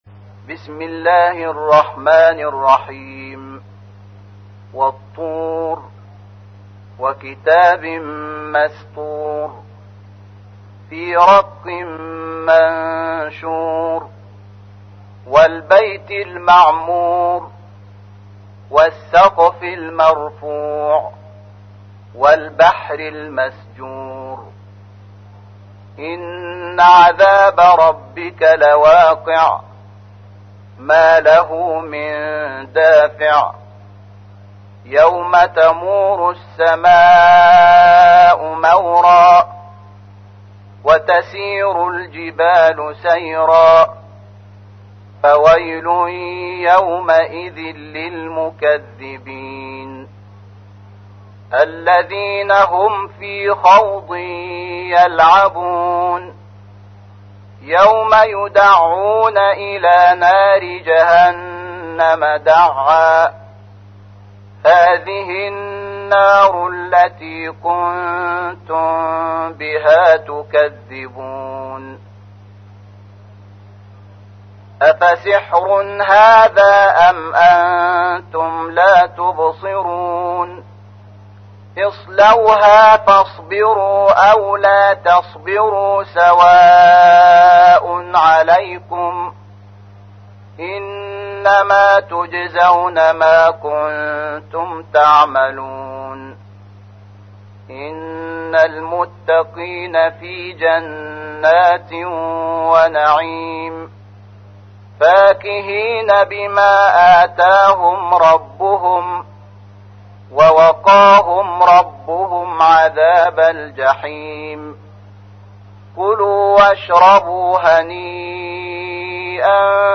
تحميل : 52. سورة الطور / القارئ شحات محمد انور / القرآن الكريم / موقع يا حسين